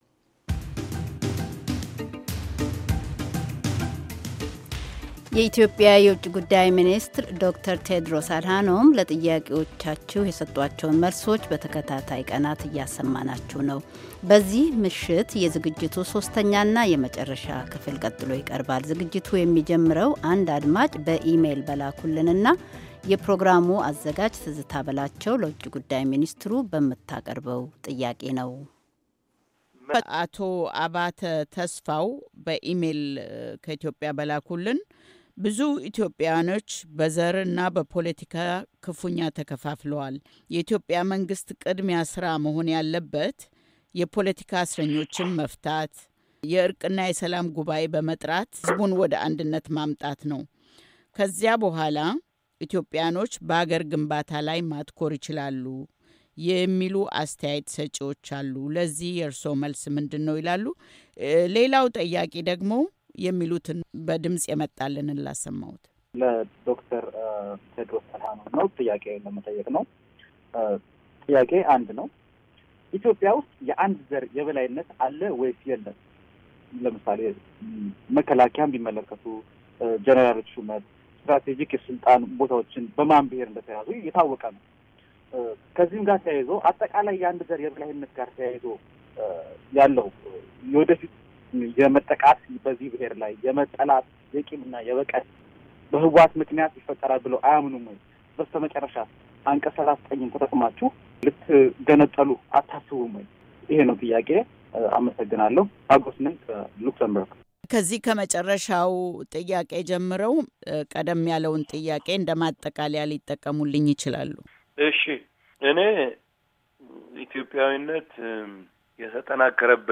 ክፍል 3፡ የኢትዮጲያ ውጭ ጉዳይ ሚኒስትር ለአሜሪካ ድምፅ ራድዮ የሰጡት ቃለ-ምልልስ
የኢትዮጵያ ዉጭ ጉዳይ ሚኒስትር ዶ/ር ቴዎድሮስ አድሃኖም በልዩ ልዩ ወቅታዊ ጉዳዮች ላይ ከአሜሪካ ድምፅ ራድዮ ጋር ተወያይተዋል።